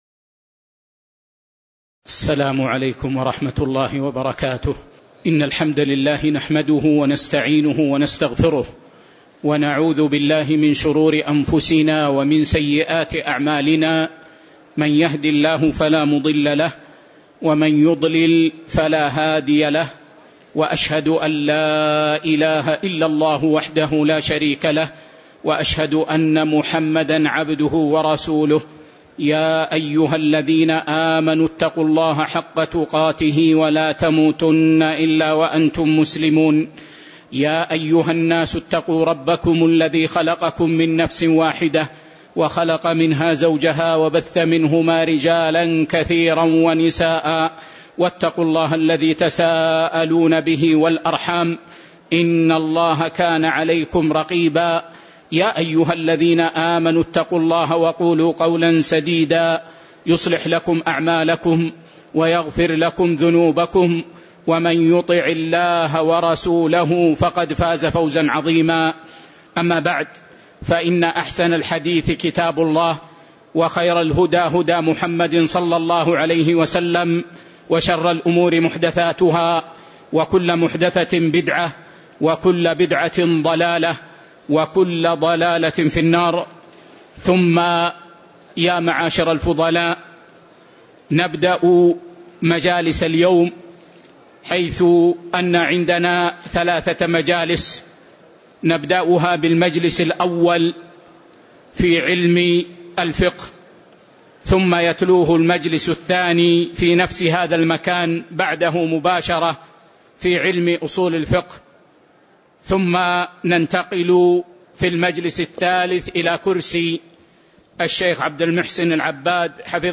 تاريخ النشر ١٩ جمادى الأولى ١٤٤٠ هـ المكان: المسجد النبوي الشيخ